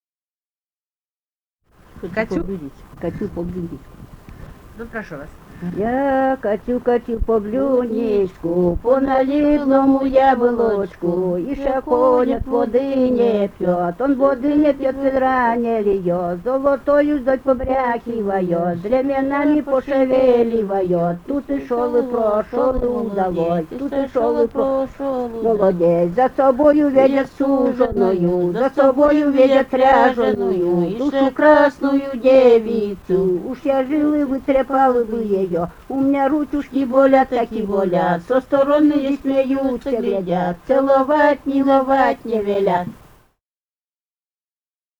полевые материалы
«Я качу, качу по блюдечку» (вечорочная).
Архангельская область, с. Долгощелье Мезенского района, 1965, 1966 гг.